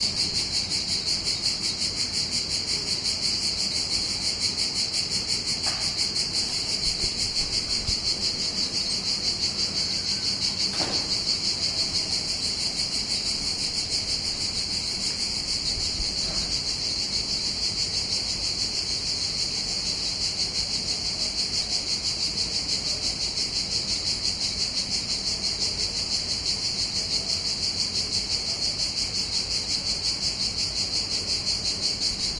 蝉的鸣叫
描述：蝉在树上唱歌。 记录在我的院子，使用Tascam DR40和Behringer B2 Pro设置为全向。
标签： 夏季 叫声 蝉鸣
声道单声道